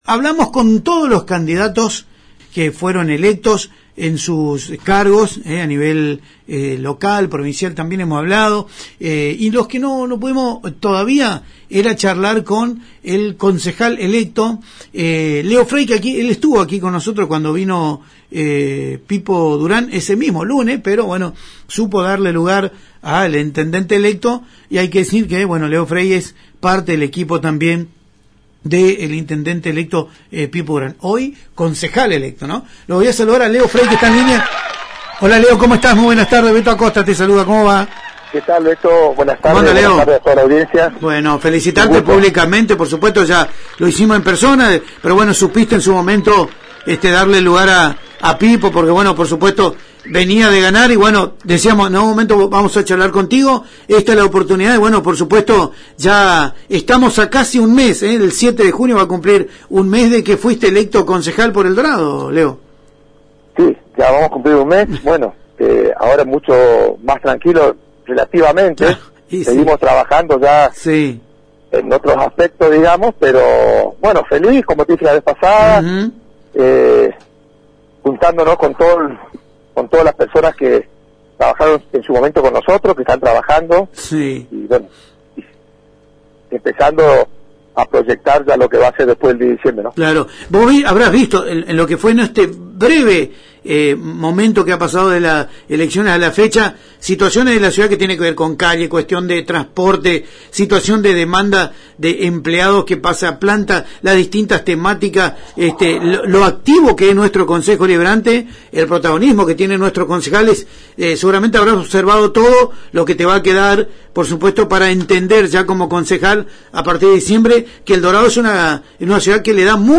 A un mes de las elecciones, en comunicación telefónica con el programa Radio Mix de Multimedios Génesis, el edil electo señaló cuáles son los lineamientos sobre los que trabajarán los integrantes que conforman el equipo de la próxima gestión.